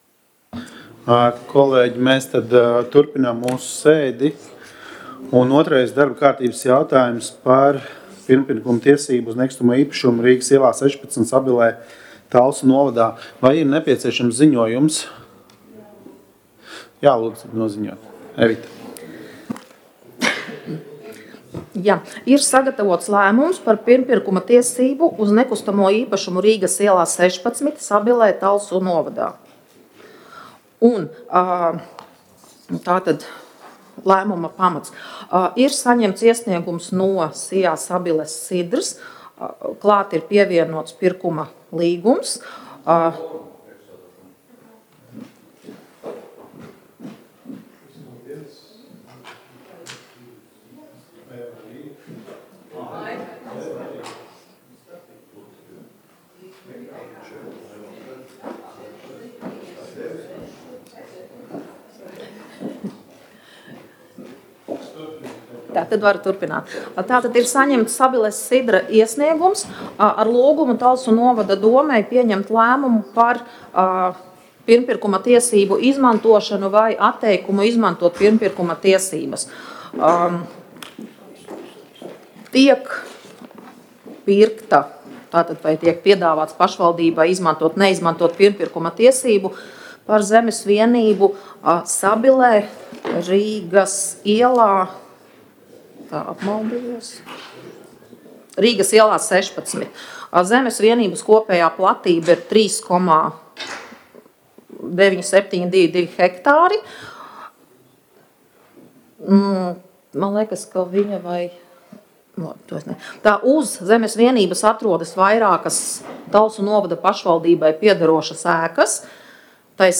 Domes sēdes audio